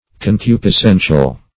Search Result for " concupiscential" : The Collaborative International Dictionary of English v.0.48: Concupiscential \Con*cu`pis*cen"tial\, a. Relating to concupiscence.